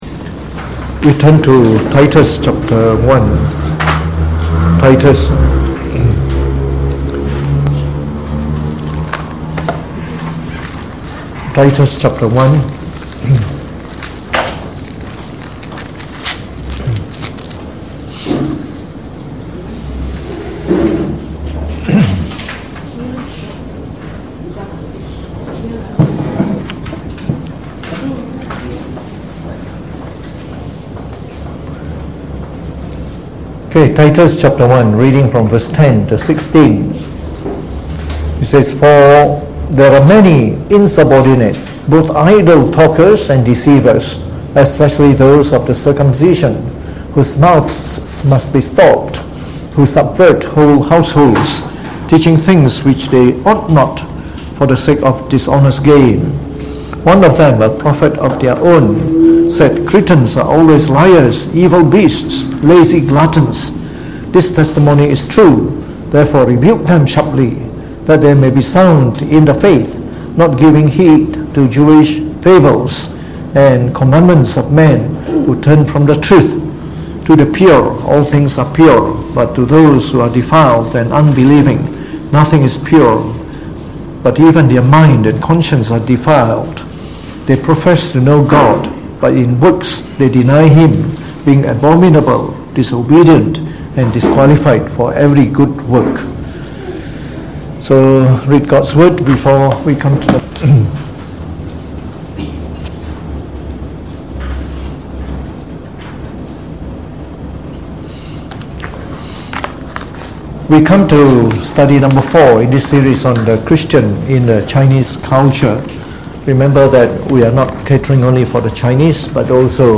Delivered on the 28th of August 2019 during the Bible Study, from the series on The Chinese Religion.